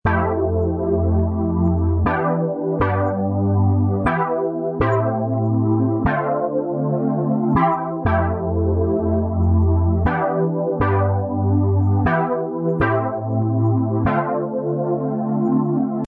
Download Vintage sound effect for free.